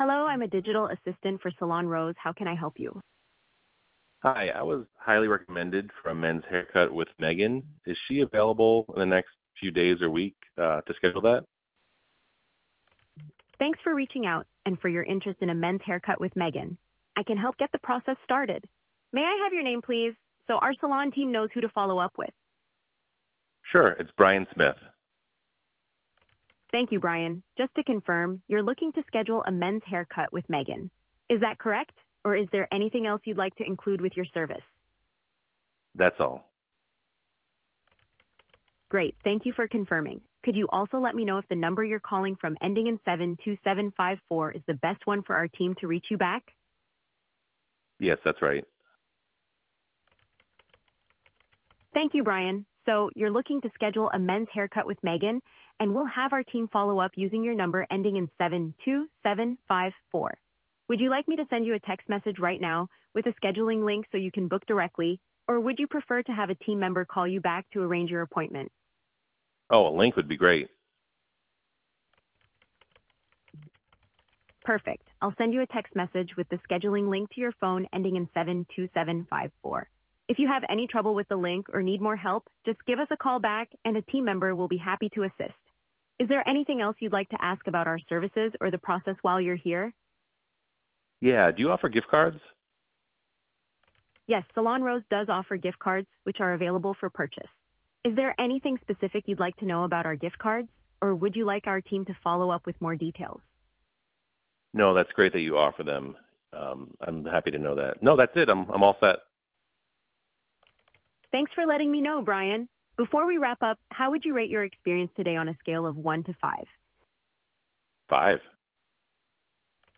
Salon-Demo-Call.mp3